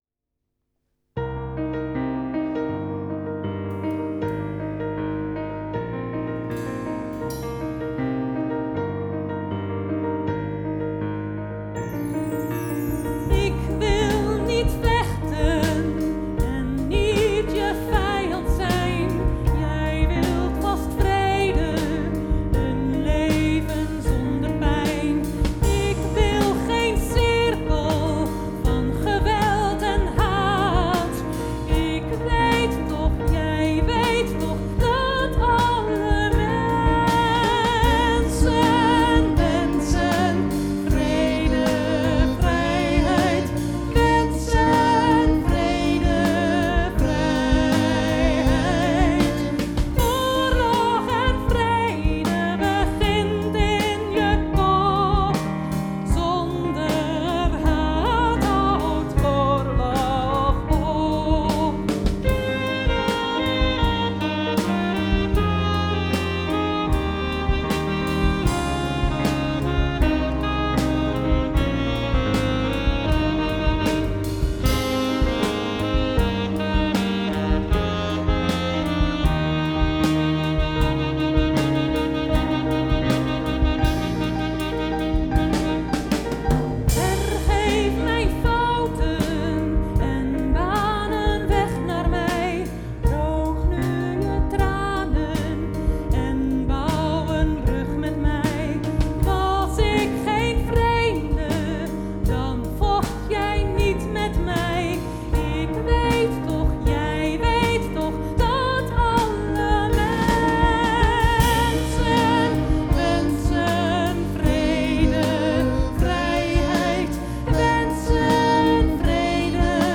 Zonder haat houdt oorlog op”, is het thema van dit lied.